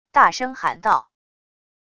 大声喊到wav音频